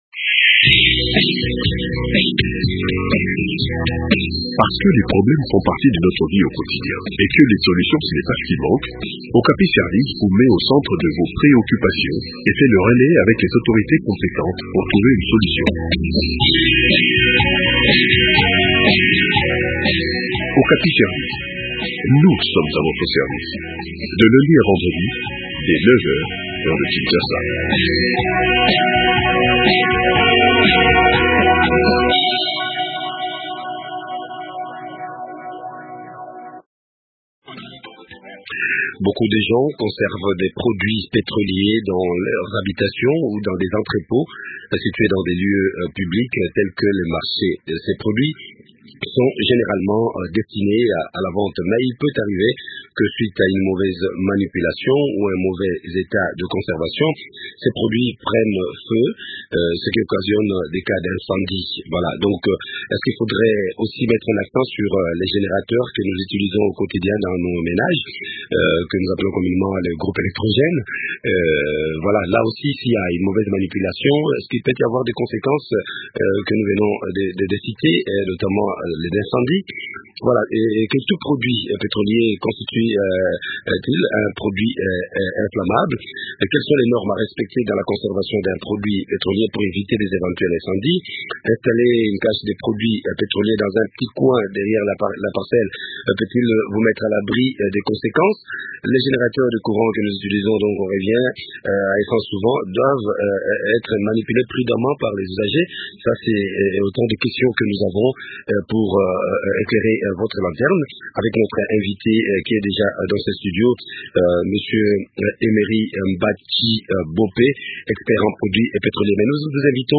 La méconnaissance des techniques de conservation et de manipulation de ces produits pétroliers serait à la base de ces incendies. Quelles sont alors ces techniques de conservation et de manipulation ? Le point du sujet dans cet entretien